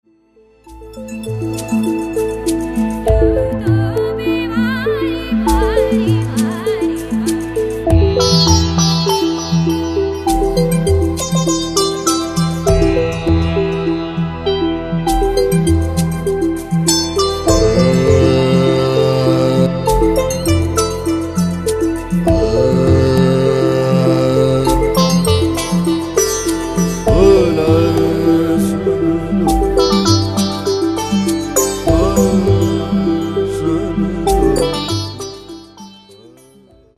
Stylowa wokaliza odkryje najgłębszą otchłań wyobraźni.